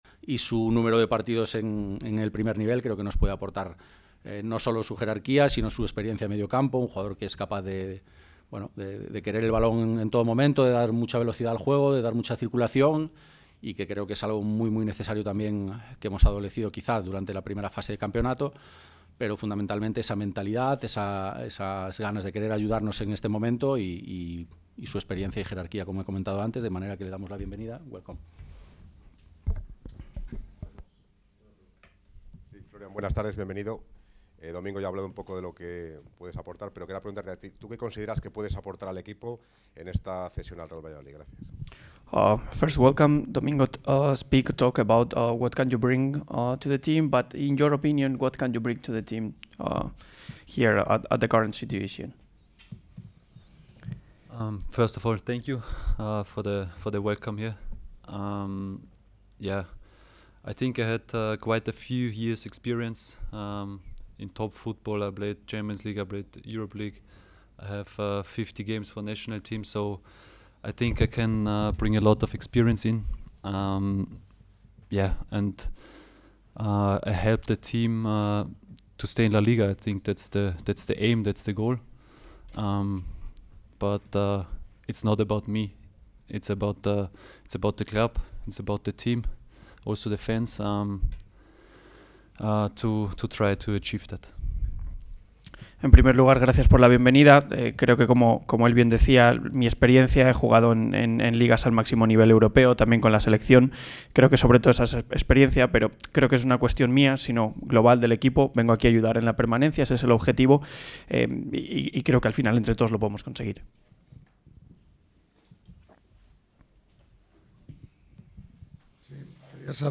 Ruedas de prensa
La sala de prensa del Estadio José Zorrilla albergó en la mañana de este miércoles la cuádruple presentación de los últimos refuerzos del Real Valladolid en el mercado invernal.